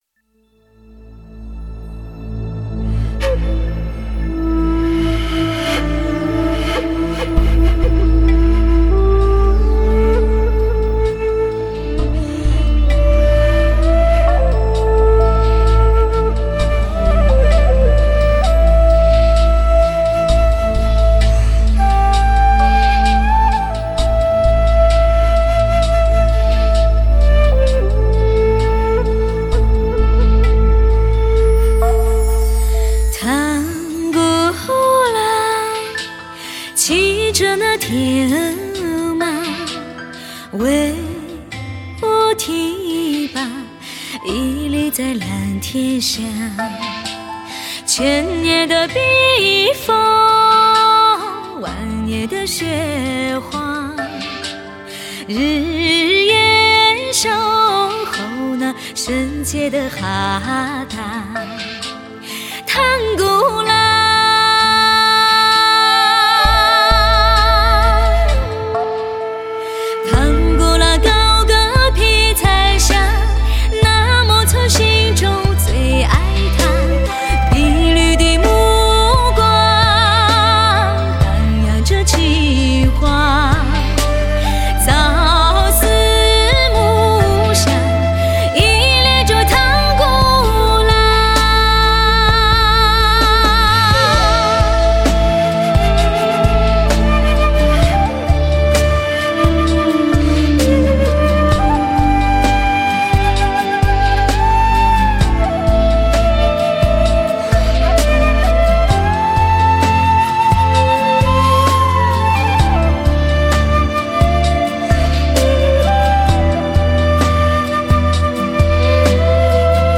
极致的HI-FI典藏 百里挑一的醇正女声 跳动的悦耳旋律
听 最唯美的女声在吟唱